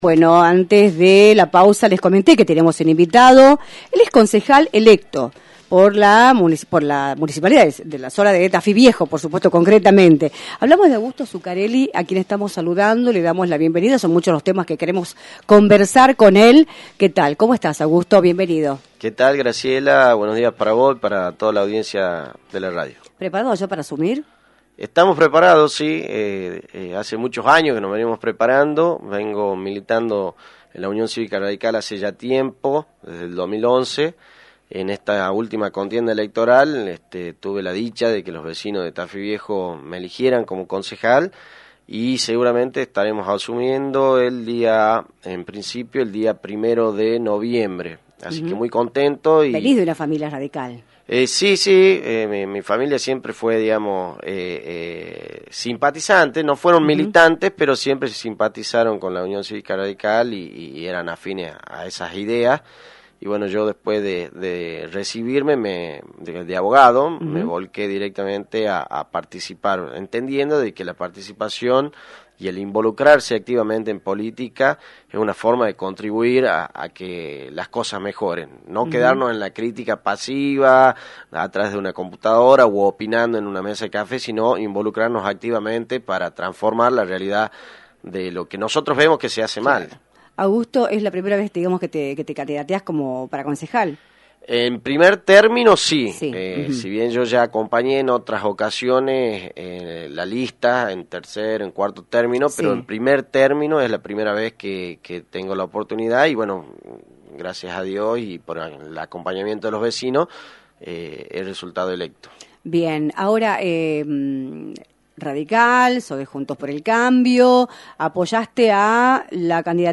Augusto Zuccarelli, Concejal electo de la Municipalidad de Tafí Viejo por Juntos por el Cambio, visitó los estudios de «Libertad de Expresión», por la 106.9 y analizó la situación política de la provincia y del país, en la previa de las elecciones PASO.